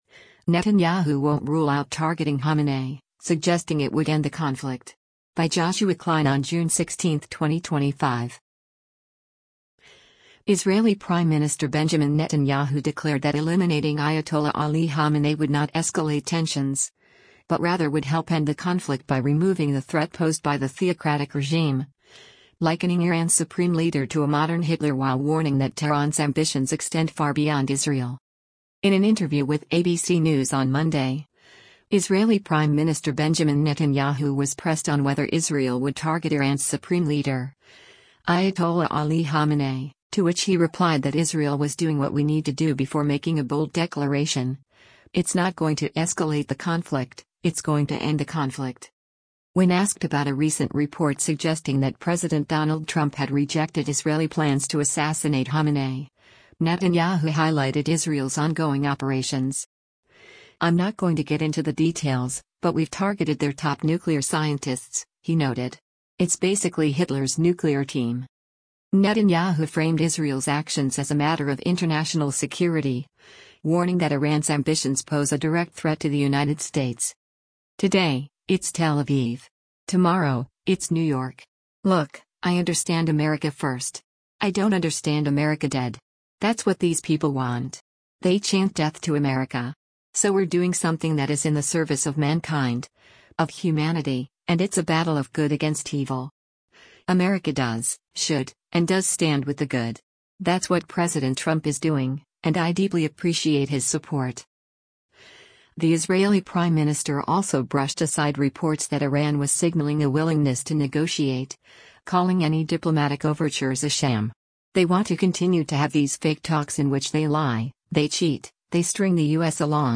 In an interview with ABC News on Monday, Israeli Prime Minister Benjamin Netanyahu was pressed on whether Israel would target Iran’s Supreme Leader, Ayatollah Ali Khamenei, to which he replied that Israel was “doing what we need to do” before making a bold declaration: “It’s not going to escalate the conflict, it’s going to end the conflict.”